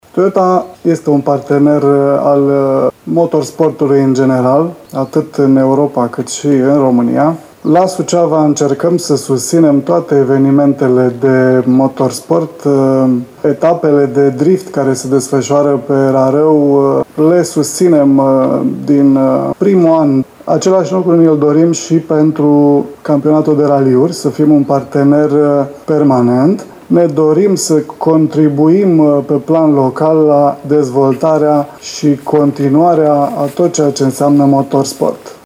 La conferința de presă de prezentare a evenimentului